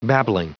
Prononciation du mot babbling en anglais (fichier audio)
Prononciation du mot : babbling